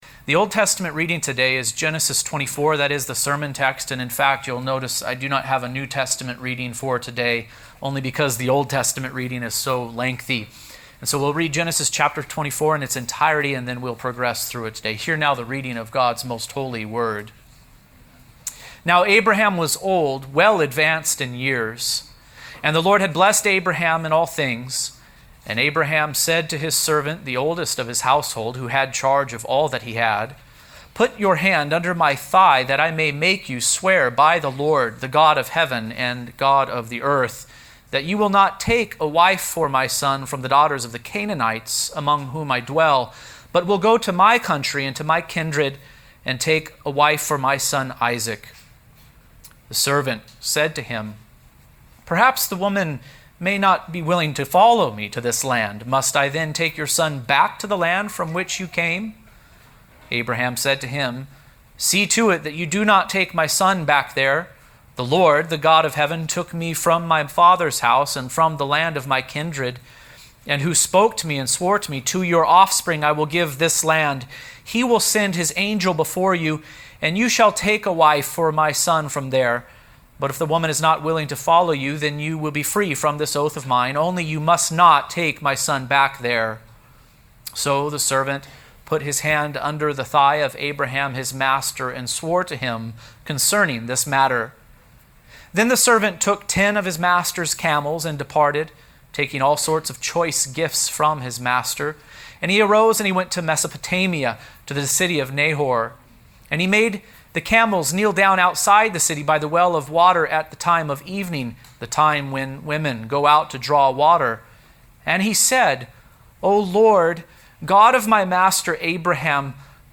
A Blessed Bride For Isaac | SermonAudio Broadcaster is Live View the Live Stream Share this sermon Disabled by adblocker Copy URL Copied!